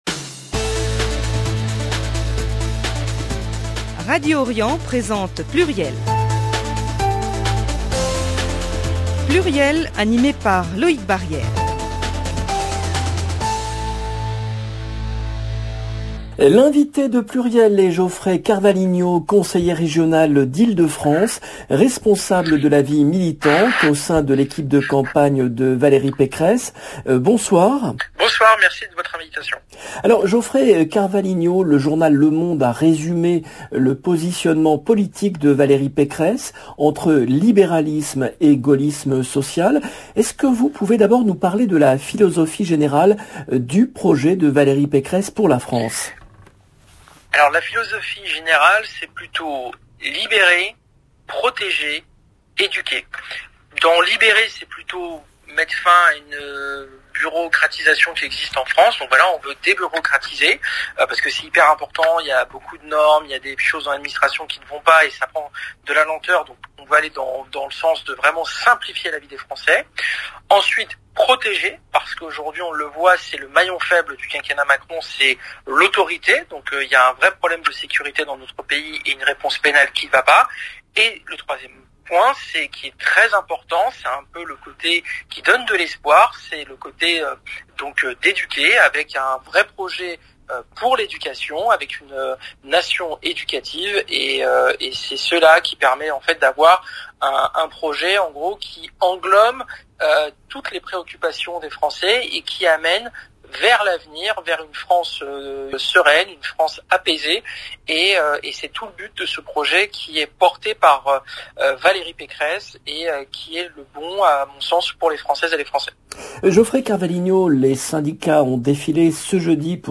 L’invité de PLURIEL est Geoffrey CARVALHINHO , conseiller régional d’Ile-de-France, responsable de la vie militante au sein de l’équipe de campagne de Valérie Pécresse.